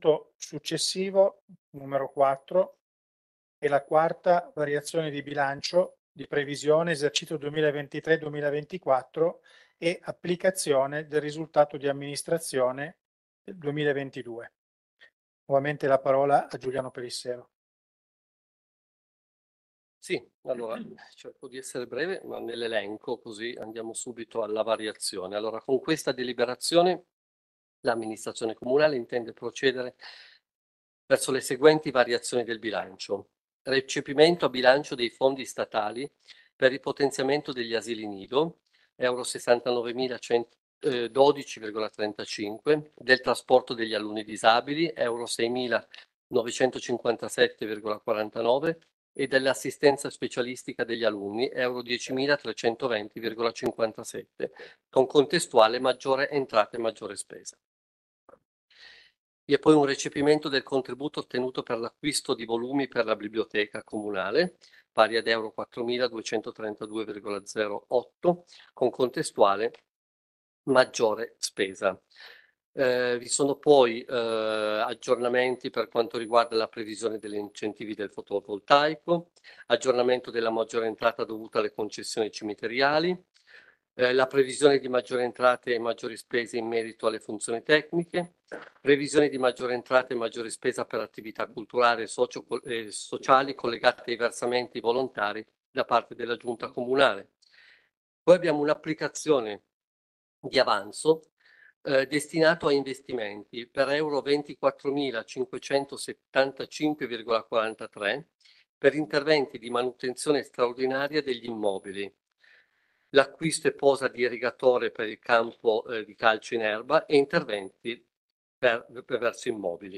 téléchargement de consiglio comunale comune susa 10_10_2023 punto 4 quarta variazione